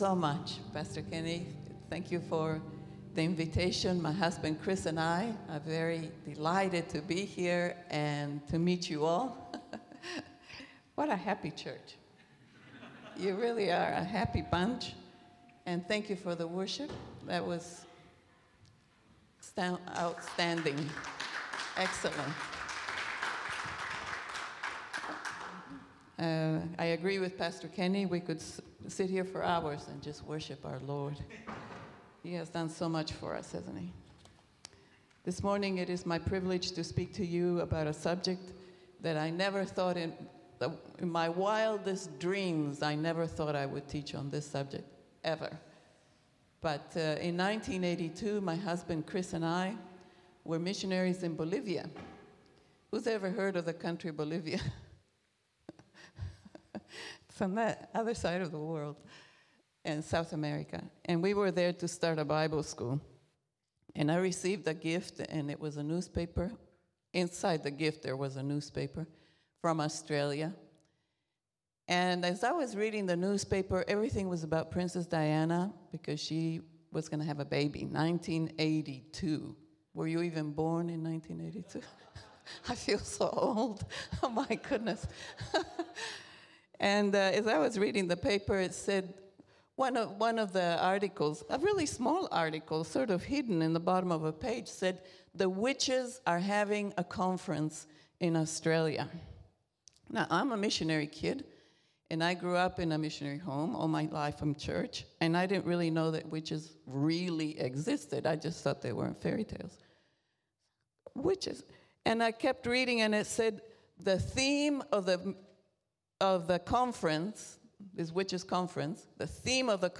English Sermons | Casey Life International Church (CLIC)
English Worship Service - 6th November 2022